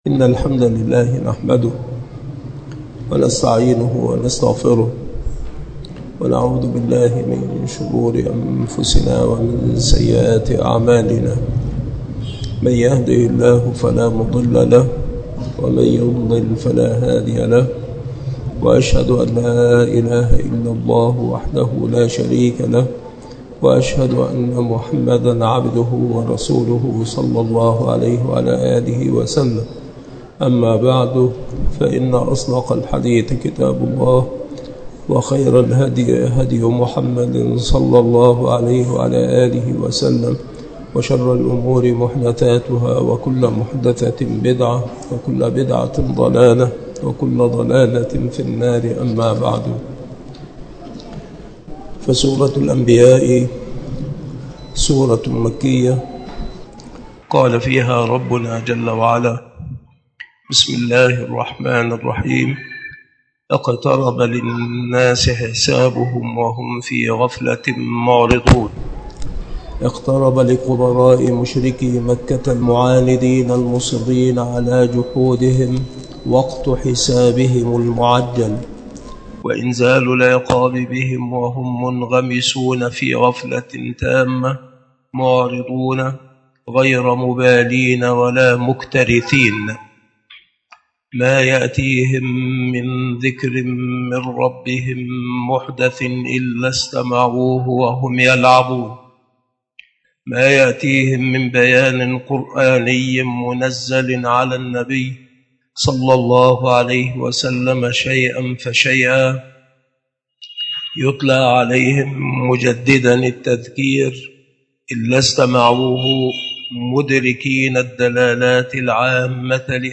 التفسير
مكان إلقاء هذه المحاضرة بالمسجد الشرقي بسبك الأحد - أشمون - محافظة المنوفية - مصر